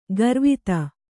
♪ garvita